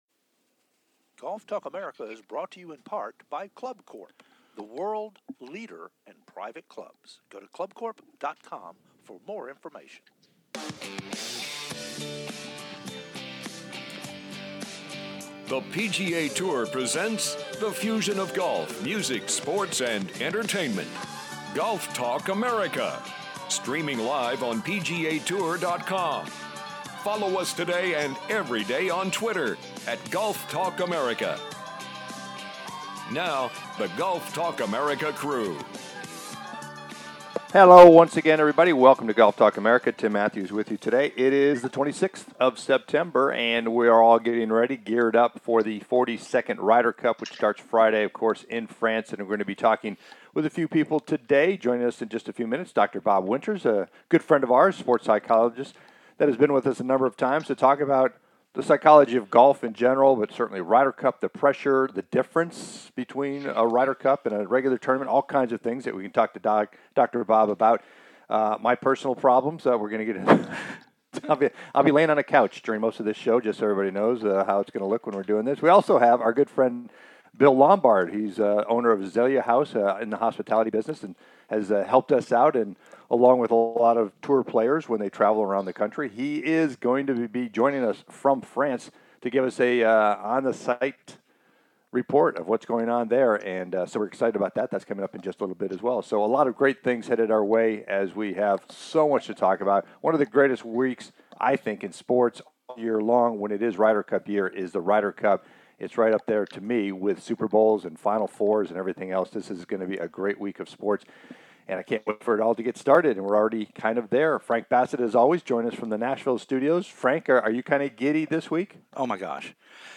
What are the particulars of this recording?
"LIVE" Reporting From The Ryder Cup